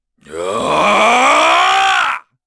Chase-Vox_Casting1_kr.wav